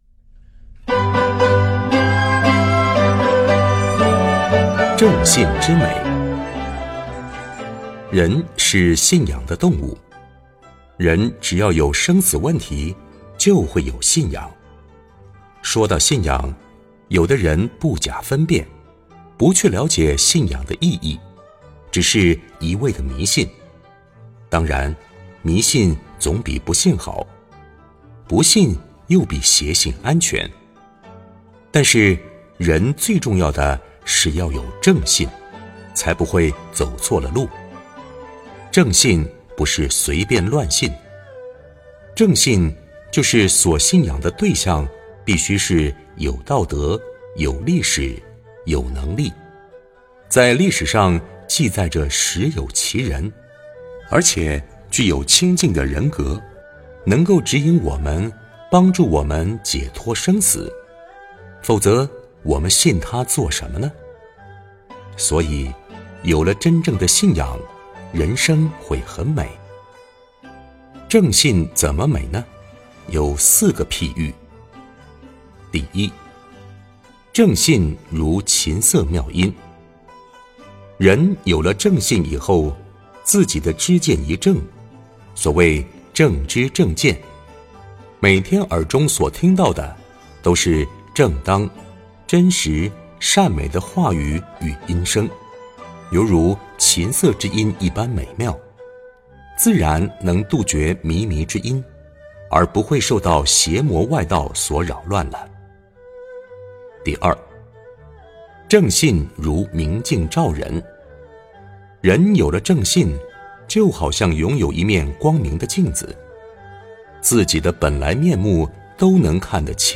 佛音 冥想 佛教音乐 返回列表 上一篇： 清净法身佛--天籁梵音 下一篇： 06.